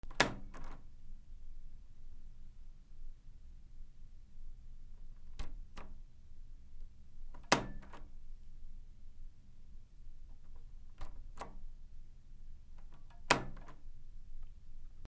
扉
/ K｜フォーリー(開閉) / K05 ｜ドア(扉)
『パ』